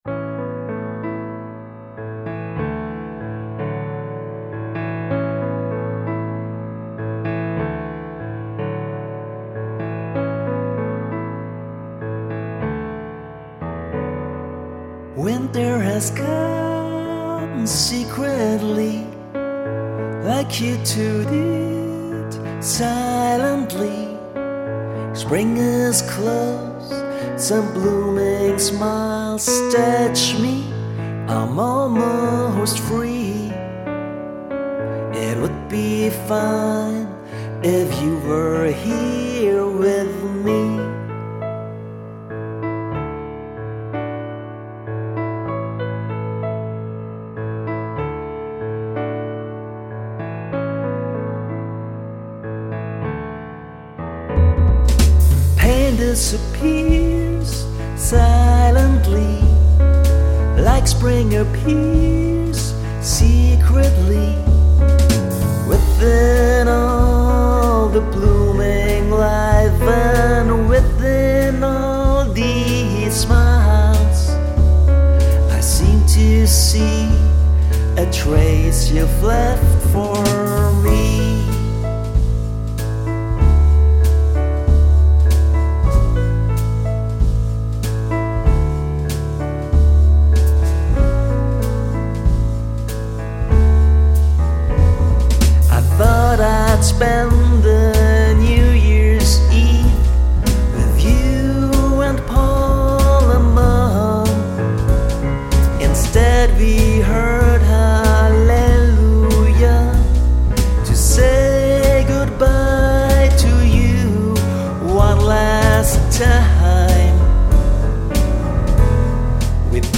vocals, piano, synth double bass, drum programming